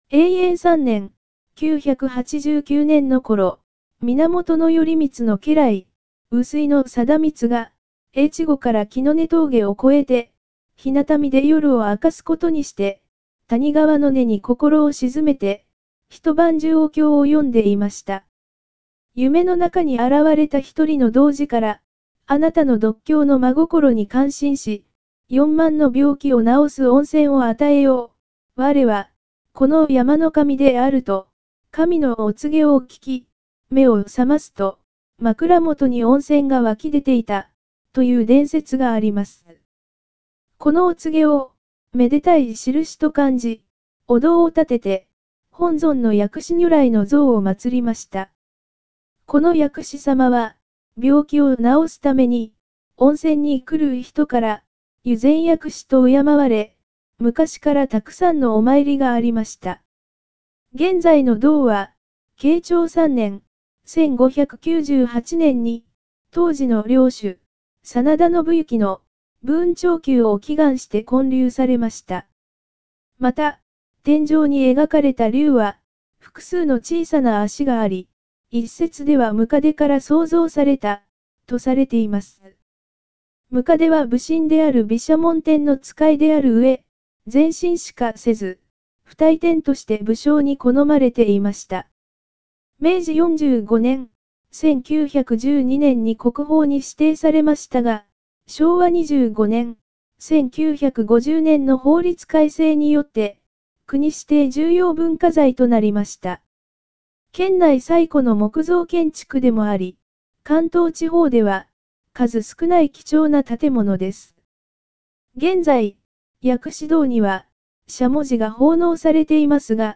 日向見薬師堂 – 四万温泉音声ガイド（四万温泉協会）
音声案内: